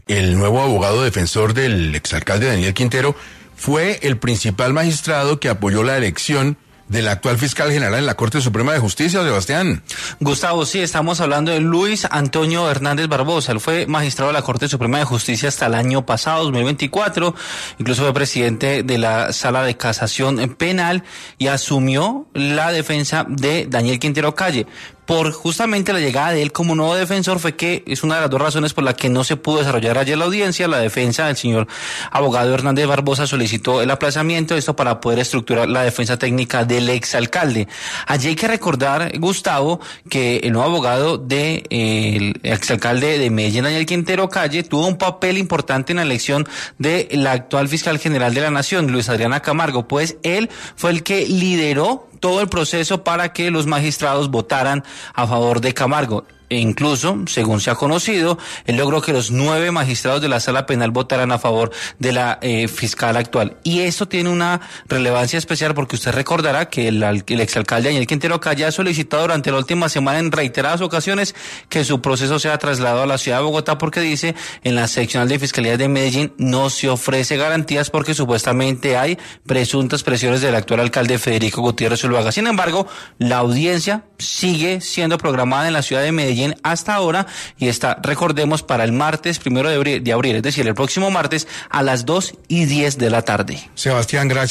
El plantón en Medellín del exalcalde Daniel Quintero antes de responder ante la justicia